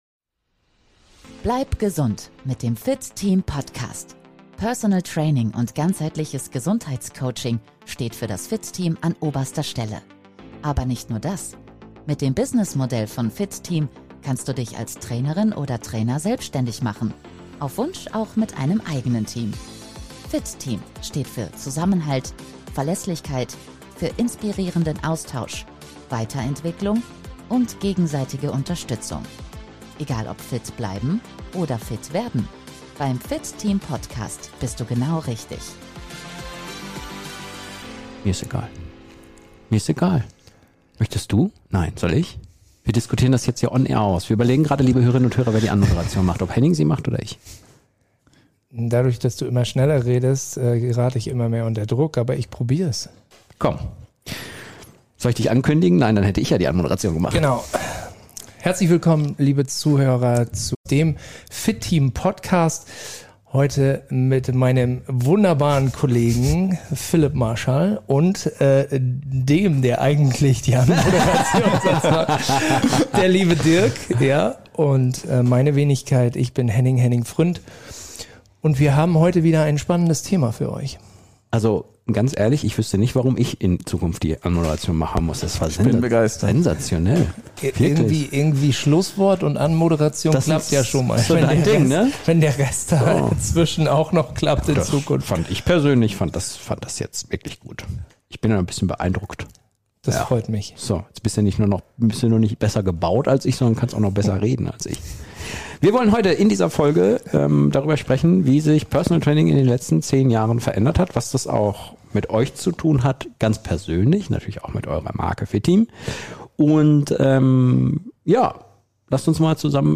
Beschreibung vor 1 Monat Heute wird es beim FIT TEAM Podcast persönlich, humorvoll – und richtig inspirierend.